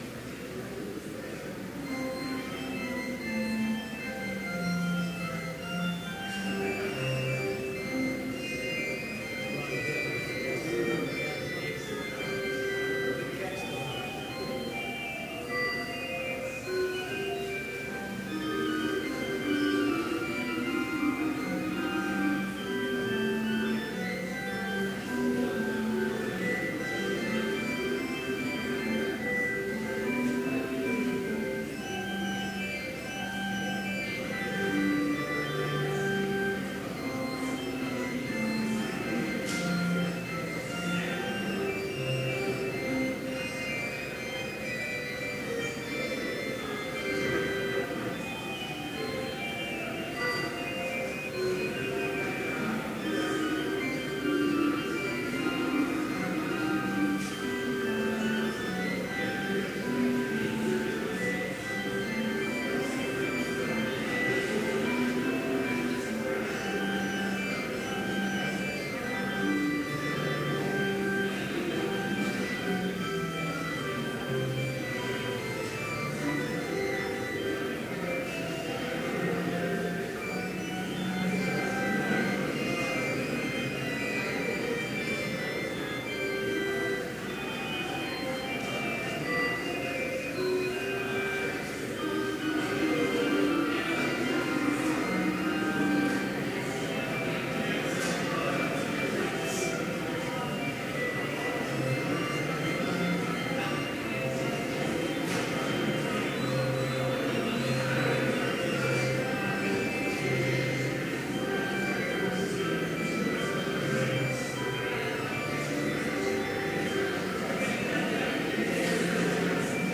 Chapel worship service in BLC's Trinity Chapel
Complete service audio for Chapel - September 18, 2017